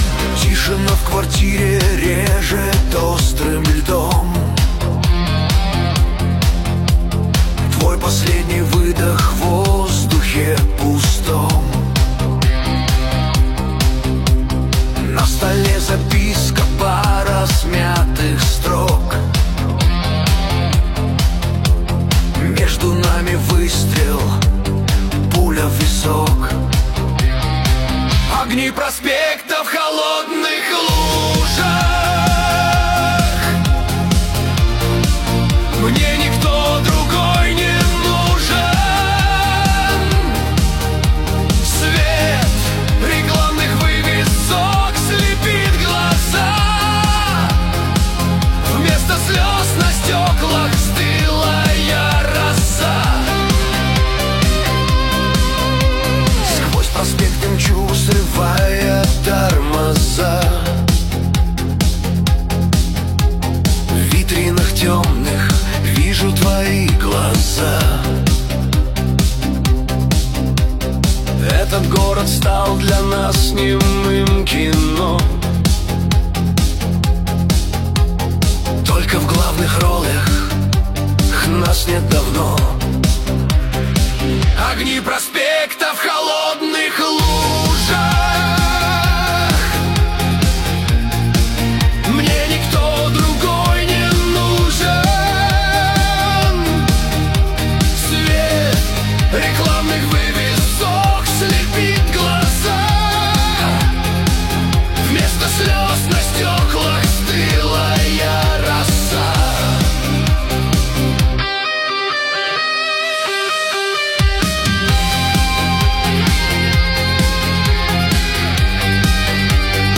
Песня ИИ